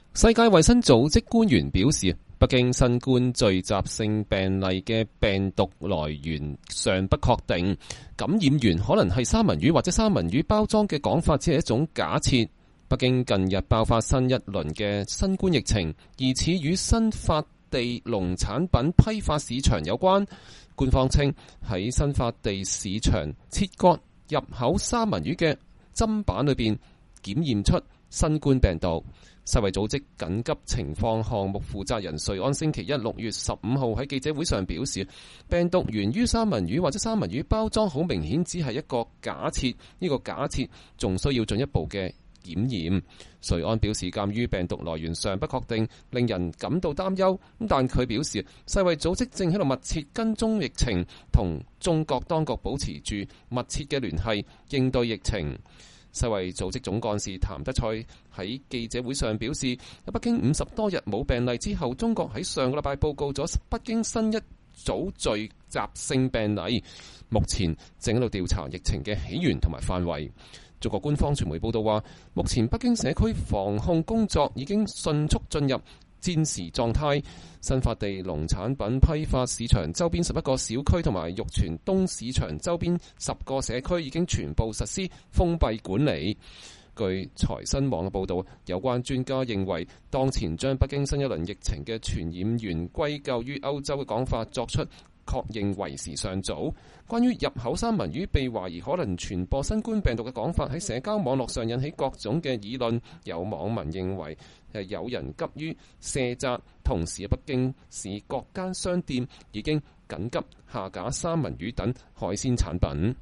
世衛組織緊急情況項目負責人瑞安在記者會上講話。（2020年2月6日）